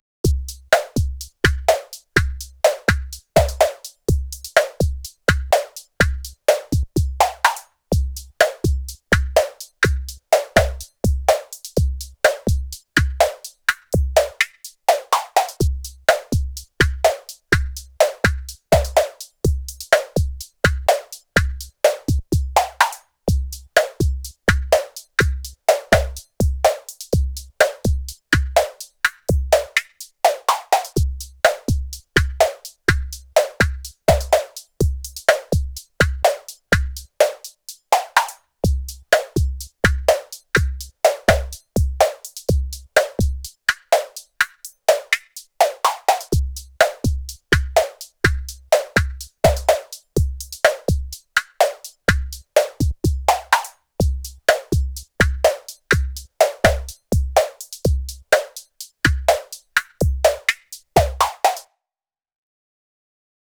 summit_test_drums.flac